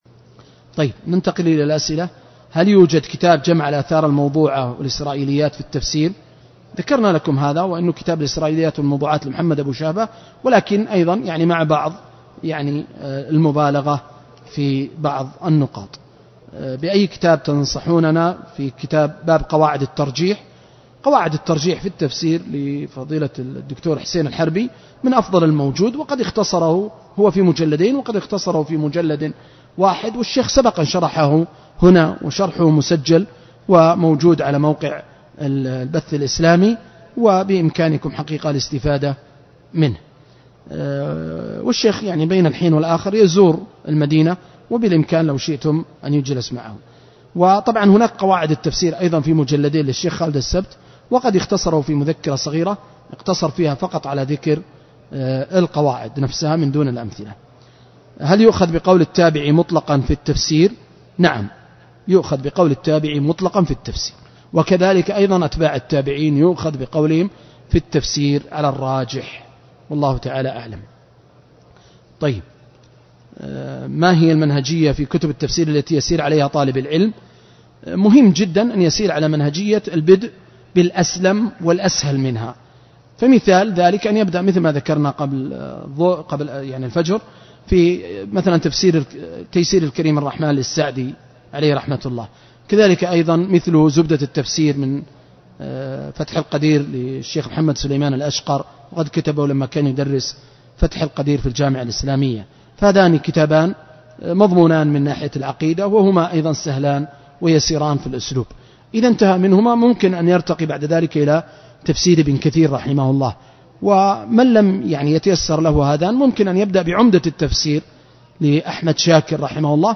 القسم السادس: أسئلة وأجوبة
دورة علمية في مسجد البلوي بالمدينة المنورة